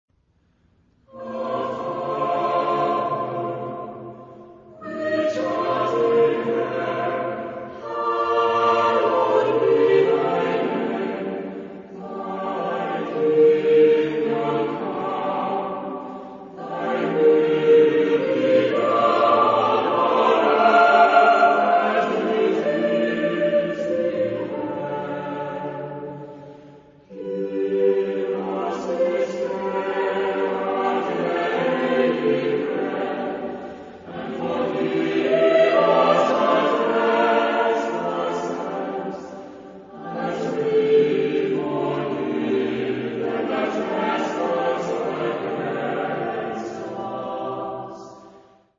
Genre-Style-Form: Sacred ; Choir ; Response
Mood of the piece: liturgical
Type of Choir: SATB  (4 mixed voices )
Tonality: F major